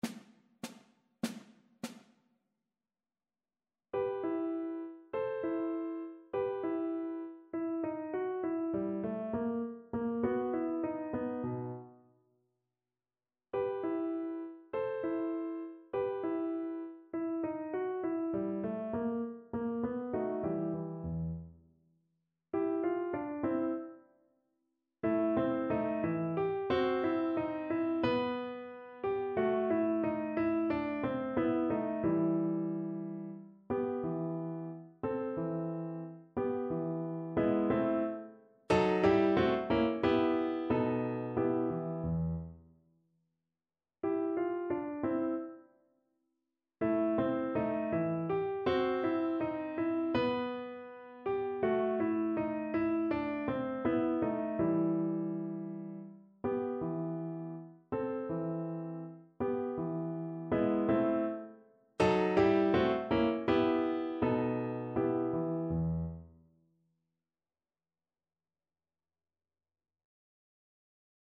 Symulacja akompaniamentu